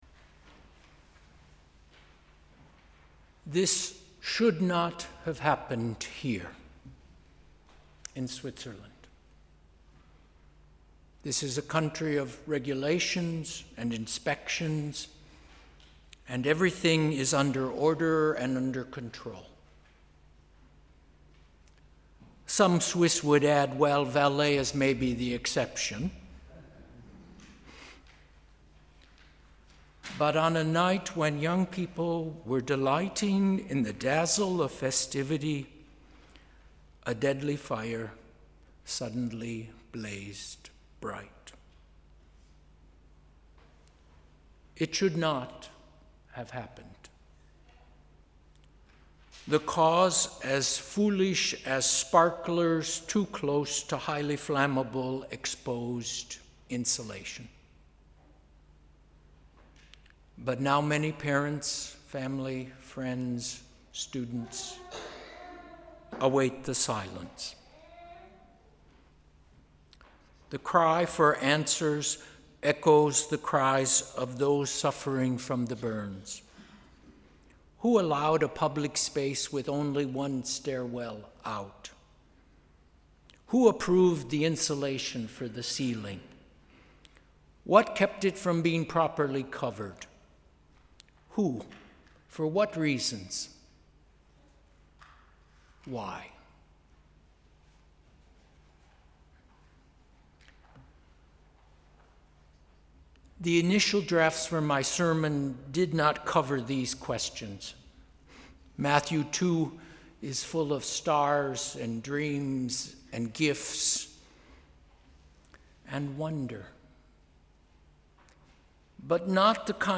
Sermon: ‘Presence for each other’